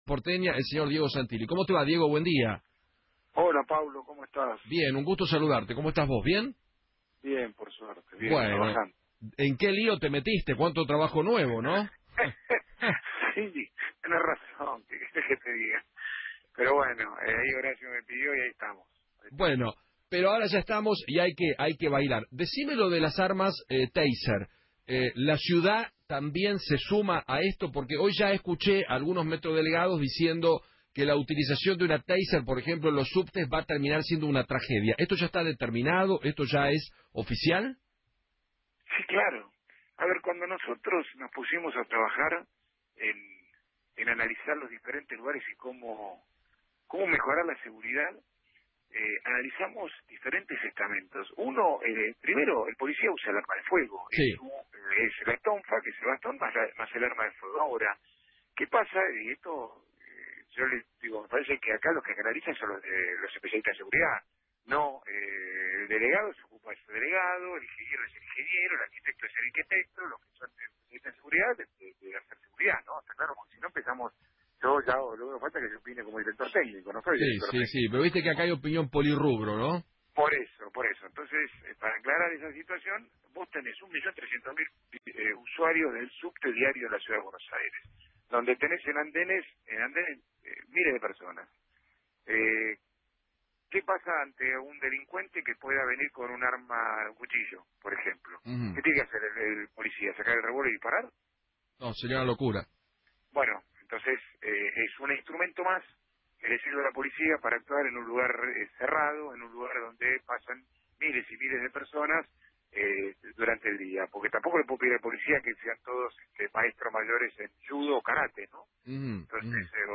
Diego Santilli, Vicejefe de Gobierno Porteño y a cargo del Ministerio de Seguridad, habló en Feinmann 910 y dijo que  “Cuando nosotros nos pusimos a trabajar en el tema de seguridad vimos que el policía usa el arma de fuego, por otro lado, los que deben hablar son los especialistas en seguridad, no los metrodelegados”